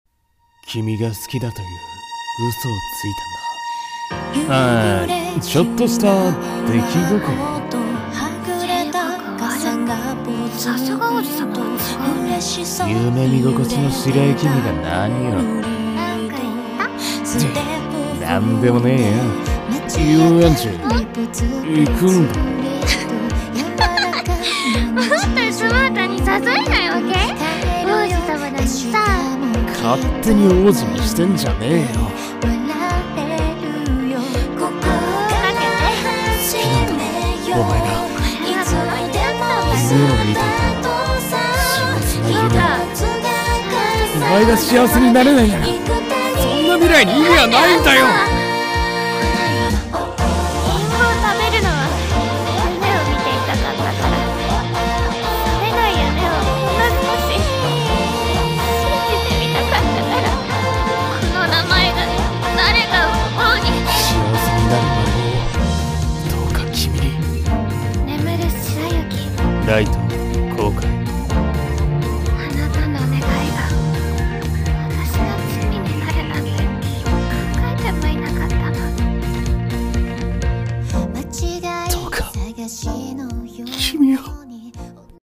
【予告風声劇】眠る白雪